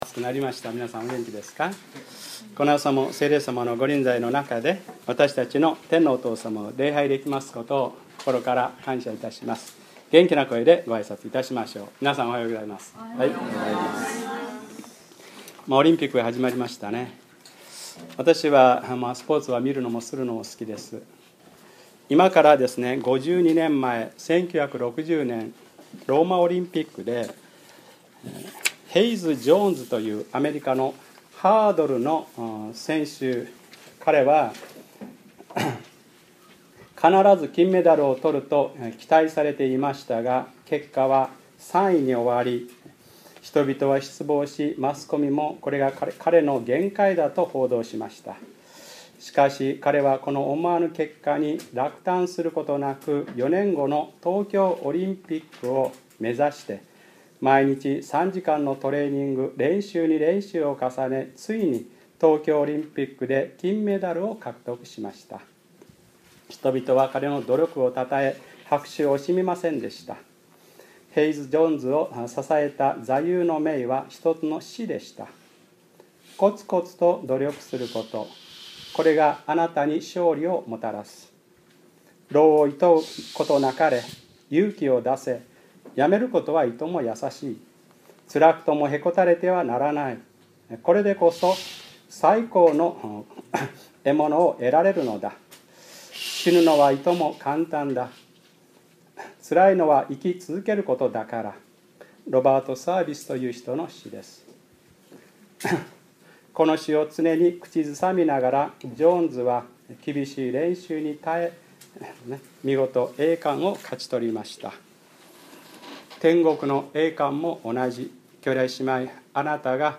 2012年7月29日(日）礼拝説教 『そのようにしてキリストの律法を全うしなさい』